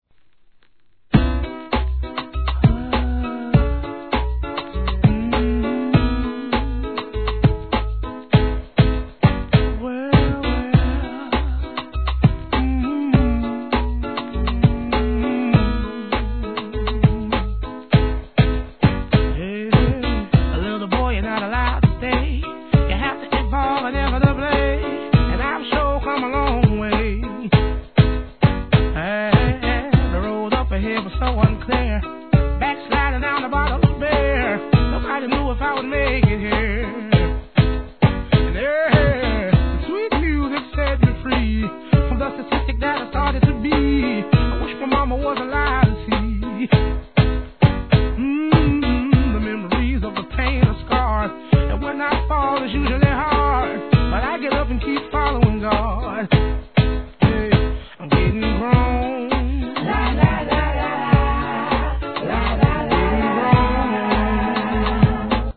HIP HOP/R&B
溜めのある哀愁トラックで見事なソウル・フル・ヴォーカル! 分かりやすいフックに、中盤の口笛もポイント!!